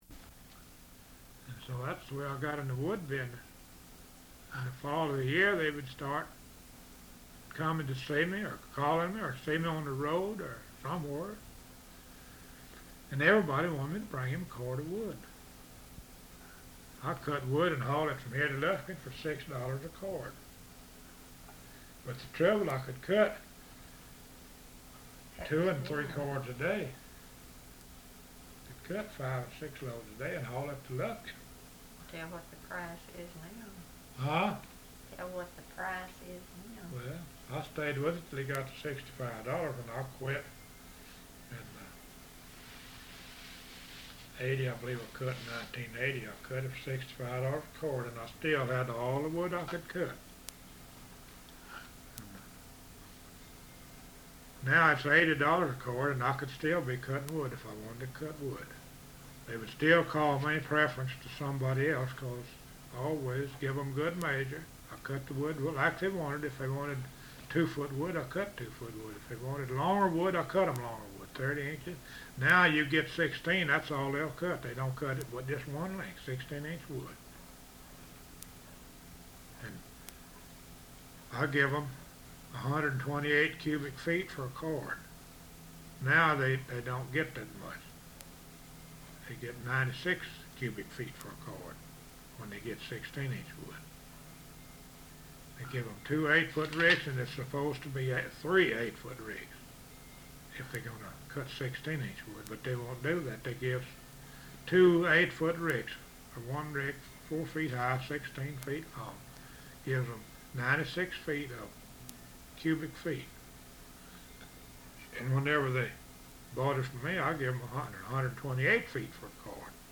Interview 53b